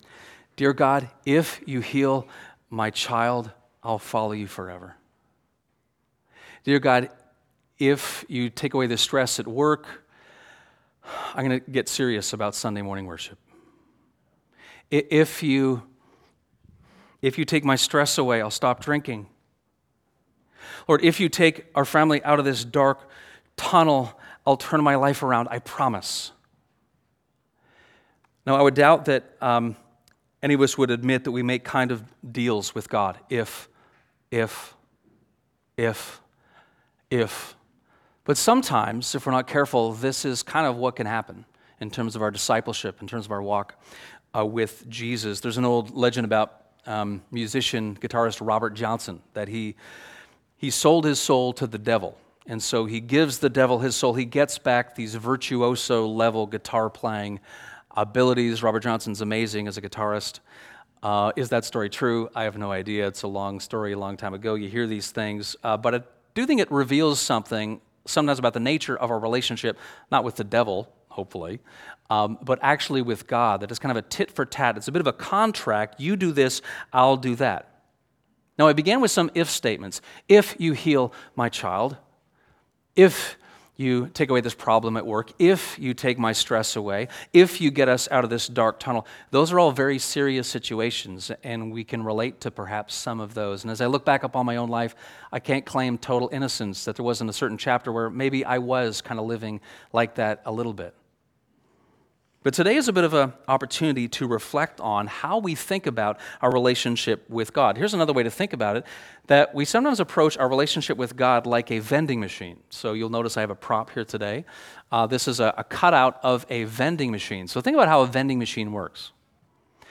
Treating God like a vending machine—and why there’s a better way (Sermon)